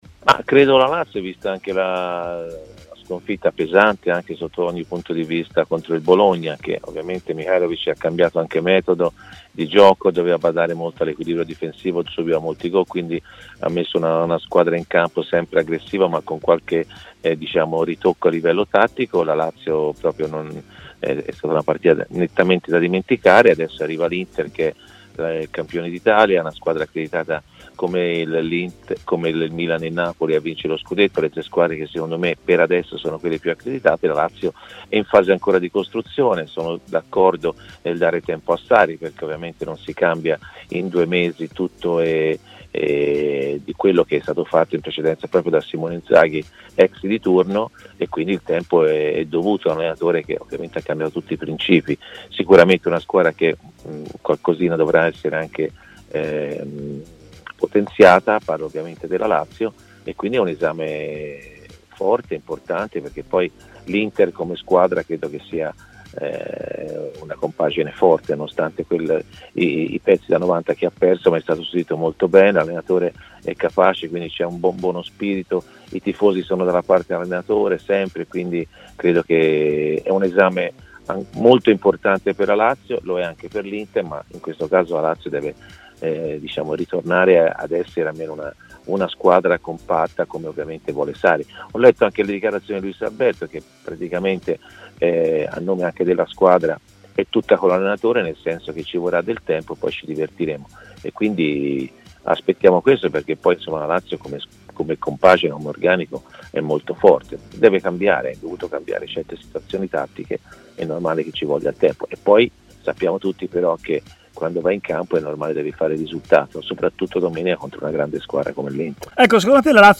Nel suo consueto intervento del giovedì a Stadio Aperto, trasmissione pomeridiana di TMW Radio, Antonio Di Gennaro ha parlato dell'attualità calcistica, tra cui anche Lazio-Inter.